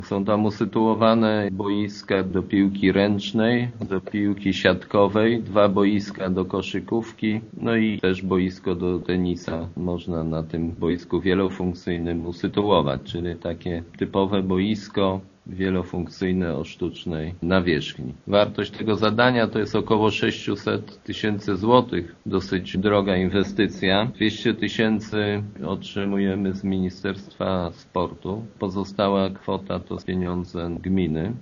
„Boisko jest przystosowane do uprawiania przynajmniej kilku dyscyplin” – mówi wójt Jacek Anasiewicz: